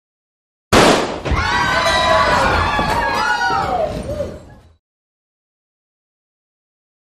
Interior Gun Shot With A Short Crowd Reaction Panic.